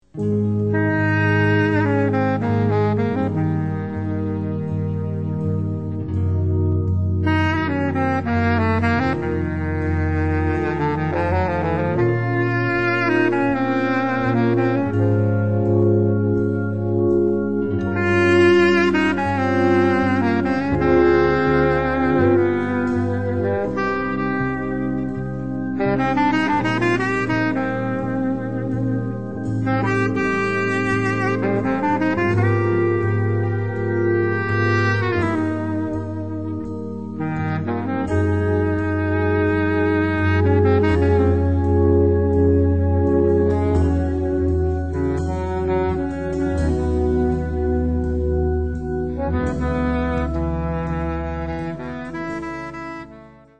Moderne Tangos/ Tango-Atmosphäre